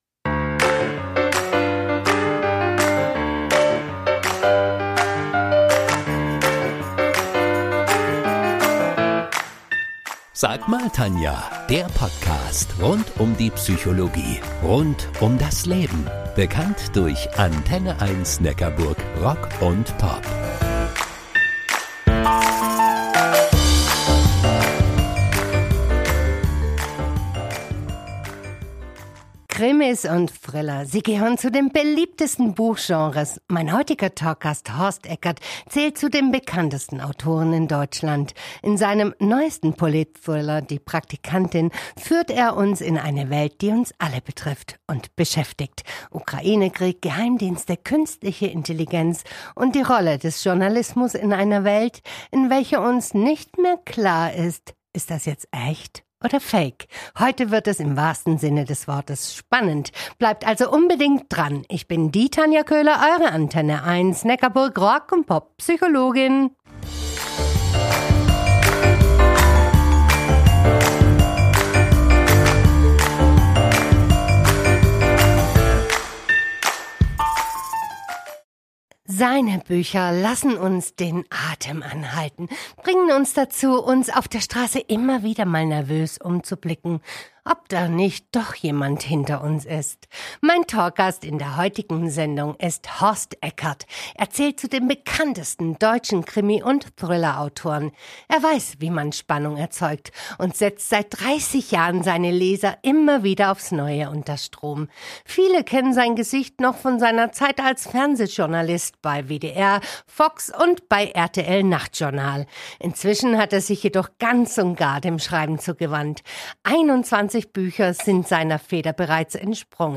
Im Gespräch mit dem Erfolgsautor Horst Eckert über seinen neuesten Politthriller